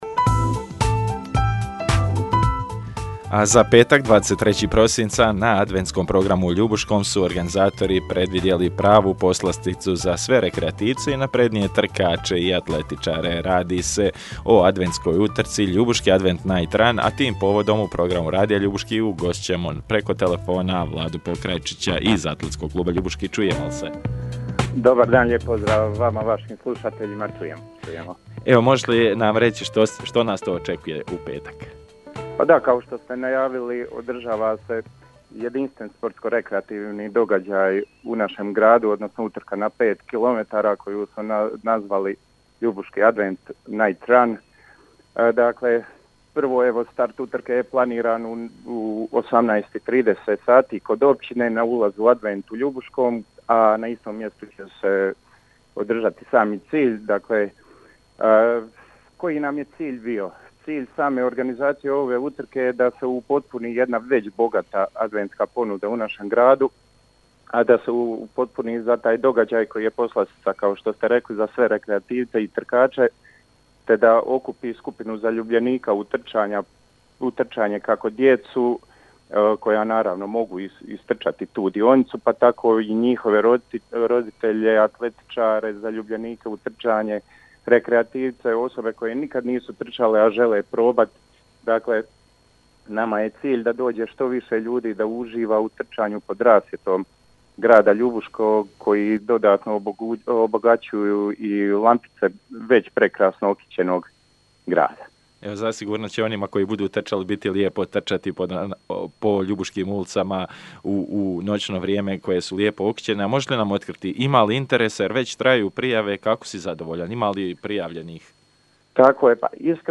u javljanju u eter Radija Ljubuški kako su se iznenadili interesom za ovu utrku.